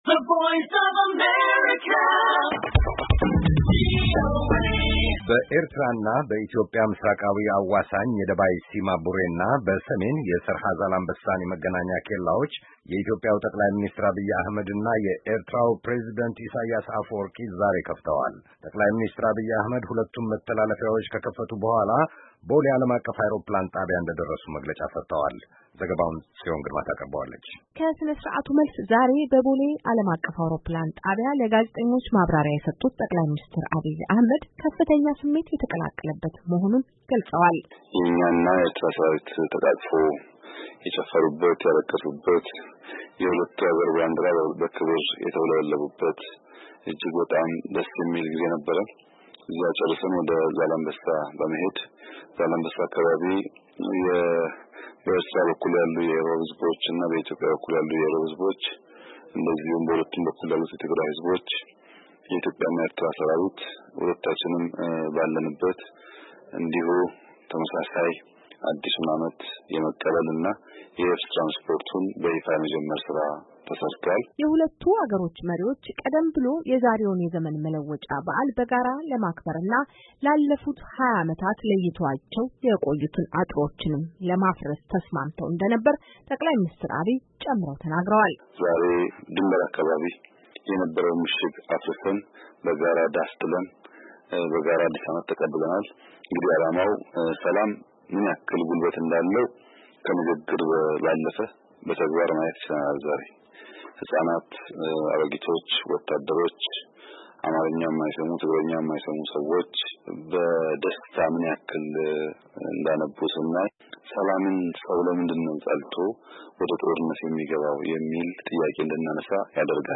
አዲስ አበባ በሚገኘው ቦሌ ዓለምአቀፍ አውሮፕላን ማረፊያ መግለጫ የሰጡት ጠቅላይ ሚኒስትር አብይ እጅግ በጣም ደስ የሚል ጊዜ እንደነበራቸው ተናግረዋል።